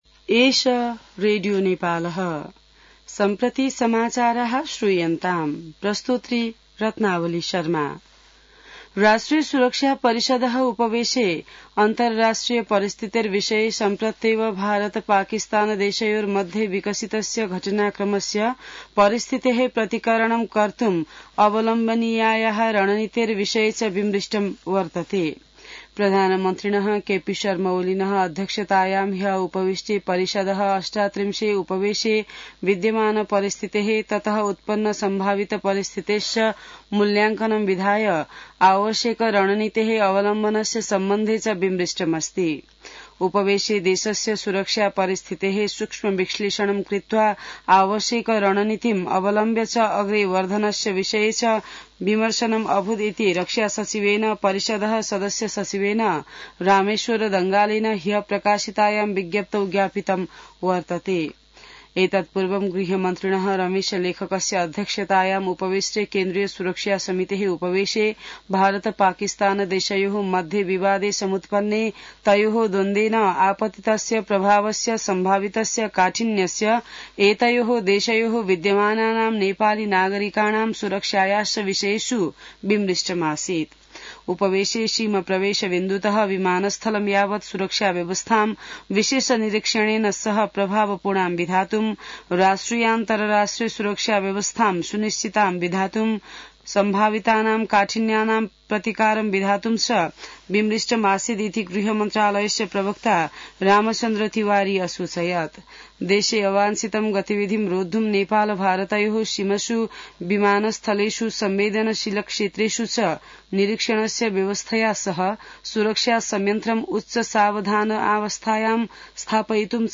An online outlet of Nepal's national radio broadcaster
संस्कृत समाचार : २५ वैशाख , २०८२